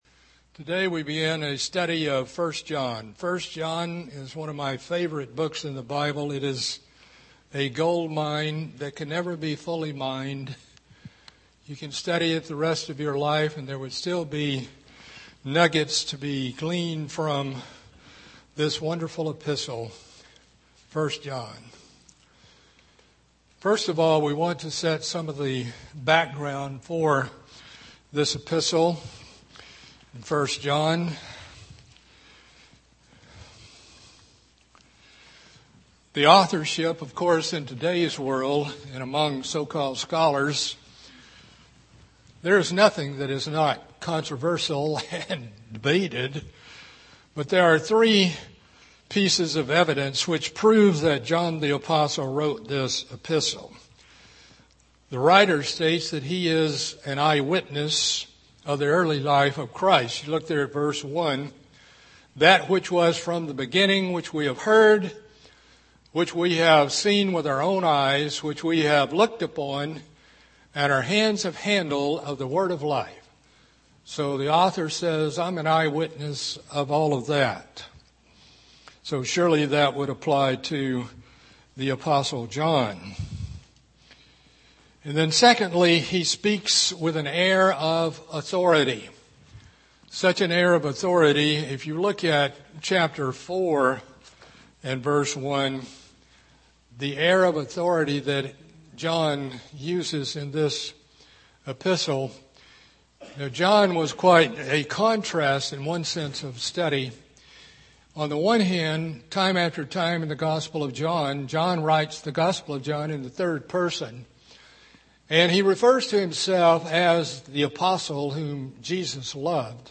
Bible study covering the book of 1 John.